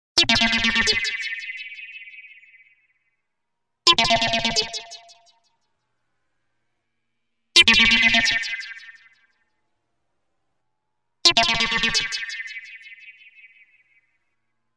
描述：电子回路（120 bpm）
Tag: 回路 电子 房子 120BPM